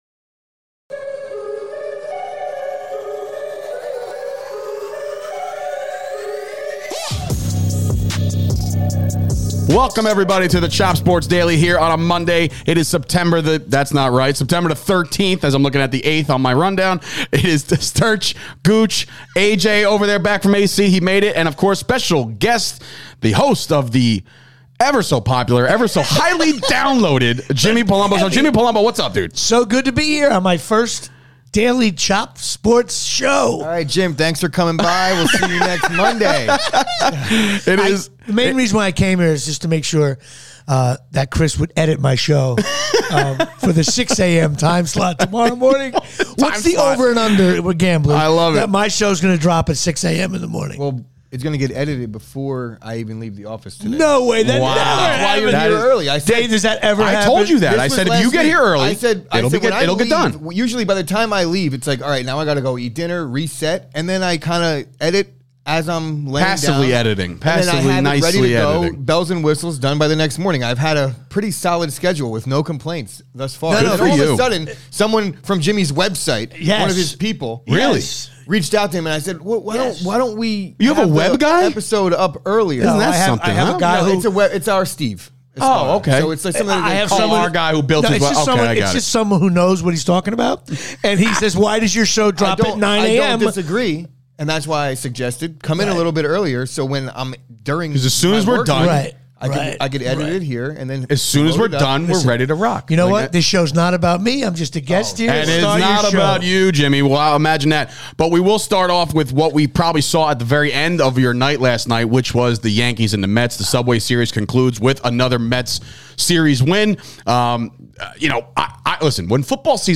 The Chop Sports Podcast is here on a Monday with a special guest in studio